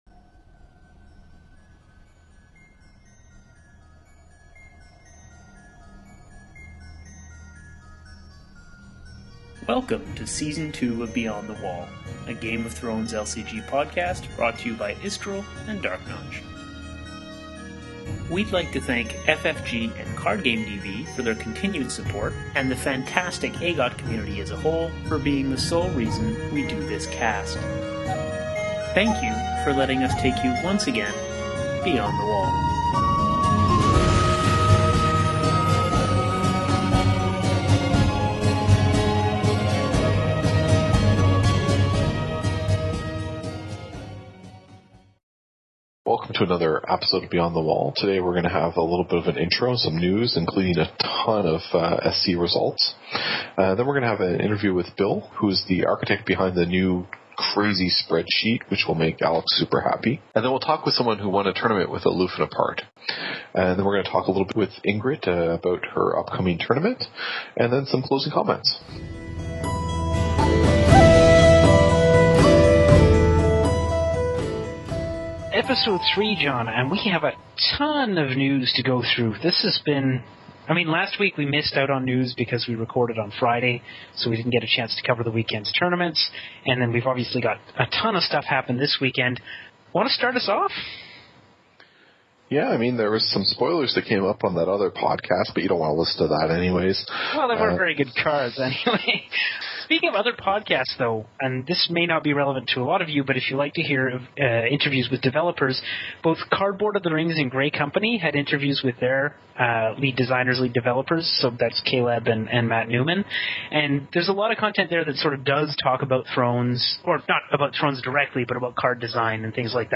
we have an exclusive interview with the first player to win a